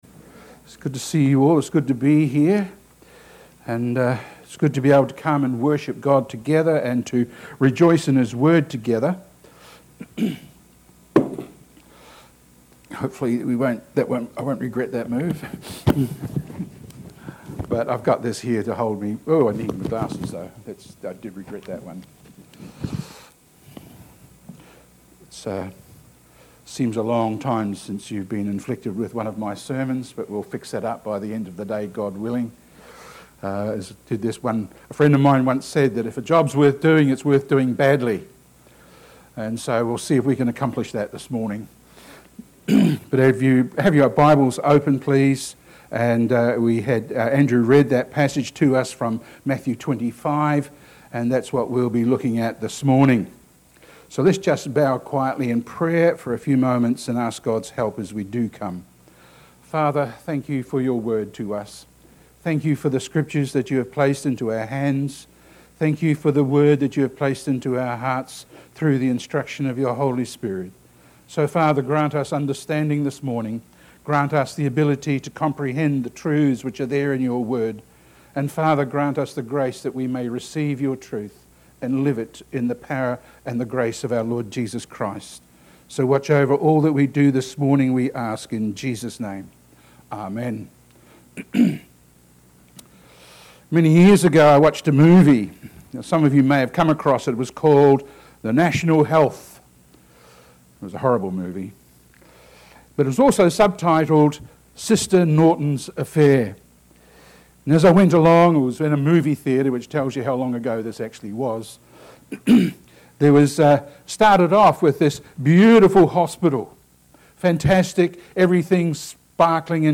We have looked at Gospel, Worship, and Discipleship recently, and we come to the end of our series on priorities for the church with a sermon on neighbour love.